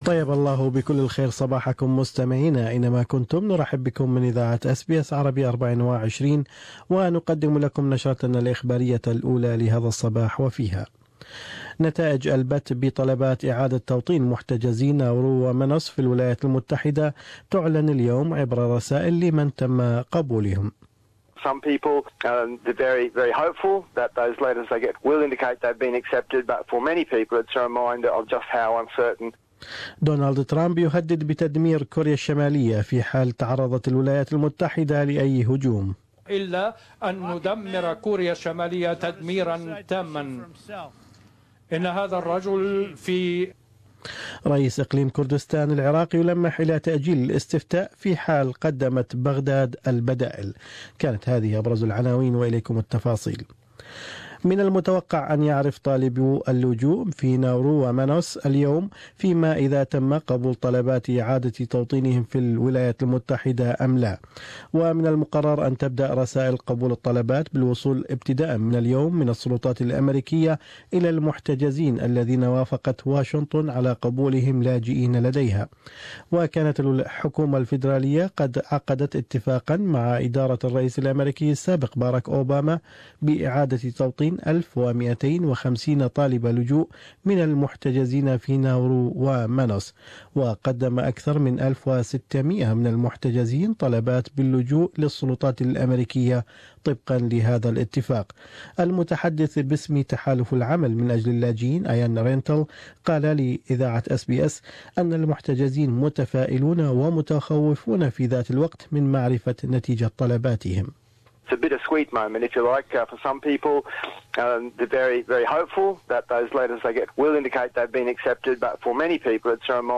News Bulletin: President Donald Trump threatens to "totally destroy" North Korea